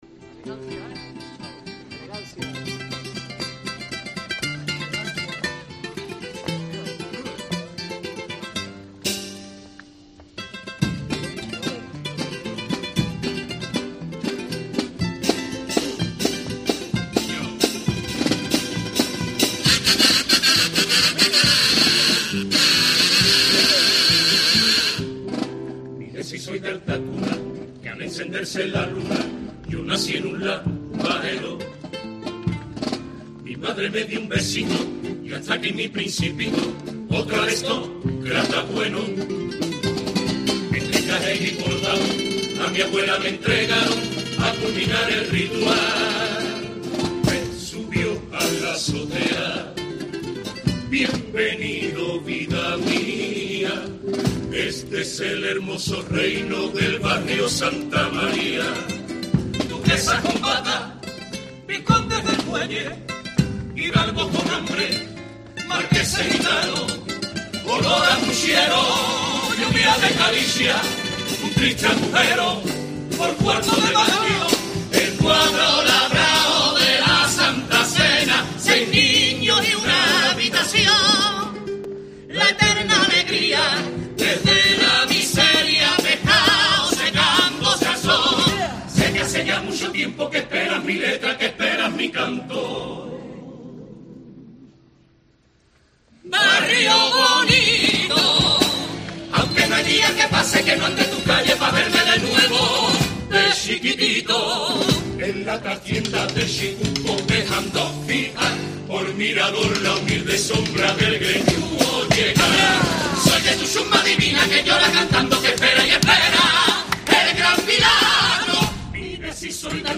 Cinco de los mejores pasodobles de comparsas del Carnaval de Cádiz 2020